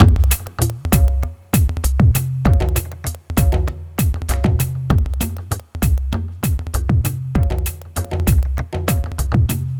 Downtempo 02.wav